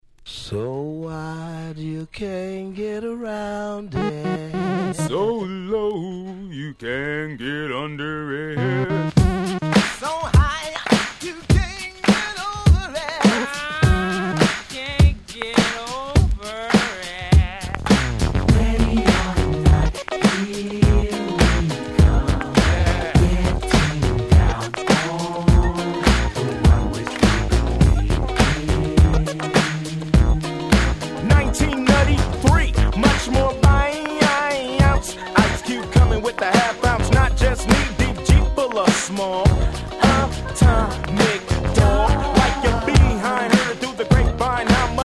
94' Big Hit West Coast Hip Hop !!
ギャングスタラップ G-Rap